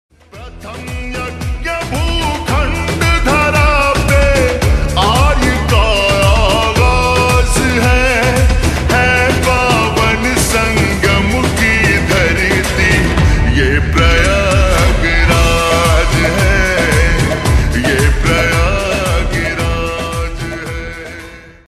Bhojpuri Song
(Slowed + Reverb)